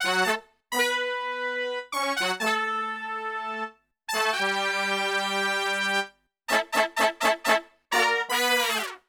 FUNK4 B M.wav